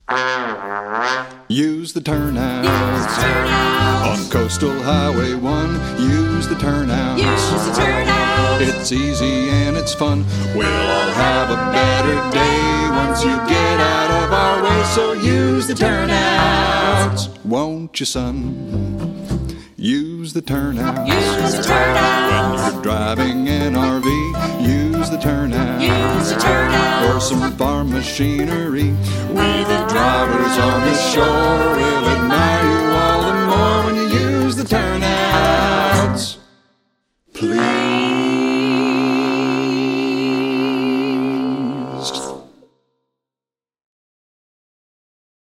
vocals, guitar
trombone
button accordion
bass
mandolin